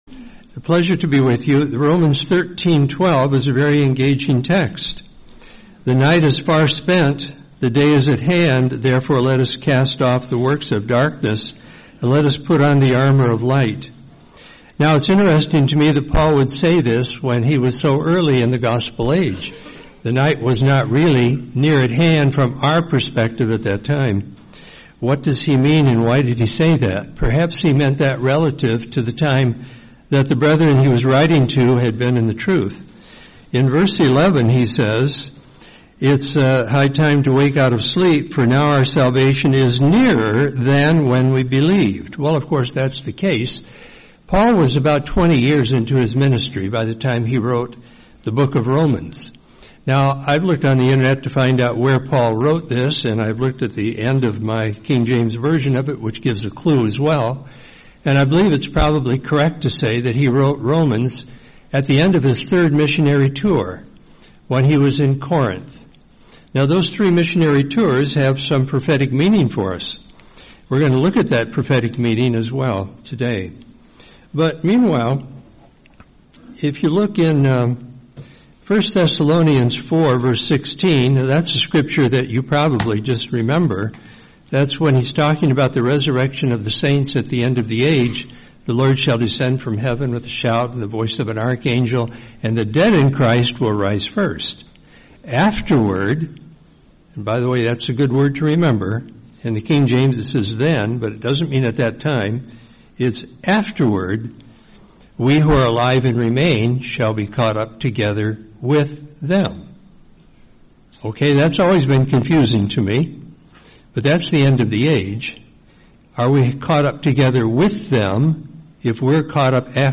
Series: 2026 Florida Convention